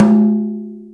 Mid Tom Drum Single Hit G# Key 14.wav
Royality free tom drum tuned to the G# note. Loudest frequency: 305Hz
mid-tom-drum-single-hit-g-sharp-key-14-n7X.mp3